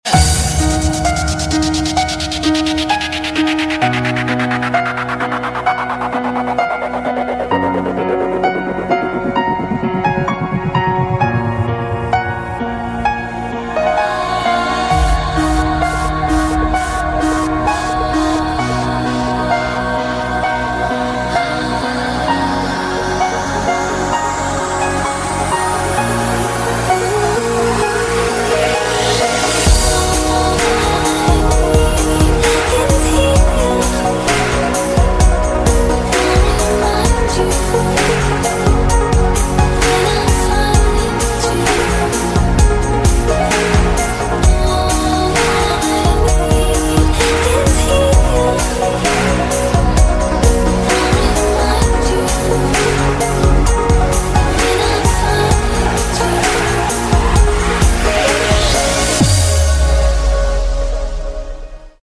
Just the sounds of Nature at Crescent Lake.